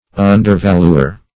Undervaluer \Un"der*val"u*er\, n.